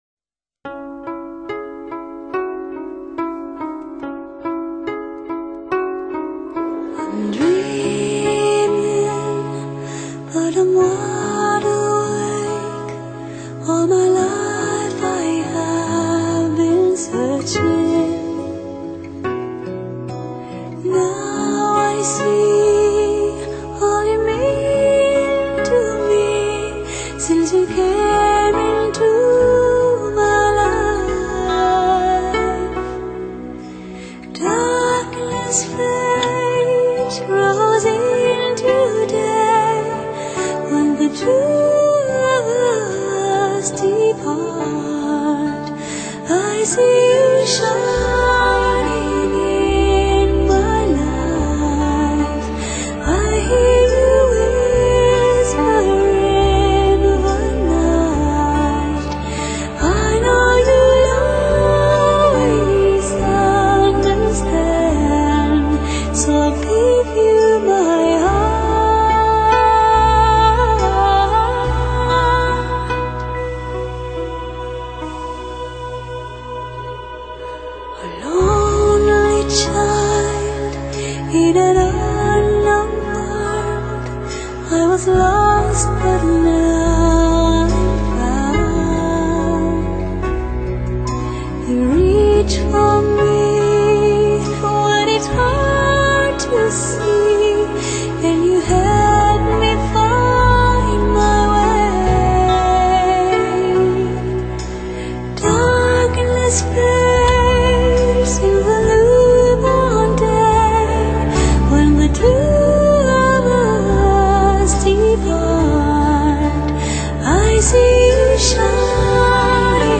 有如夜莺般美妙，白日梦似的 缥缈。
因为专辑在教堂中录制